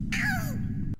GiggleCry2.mp3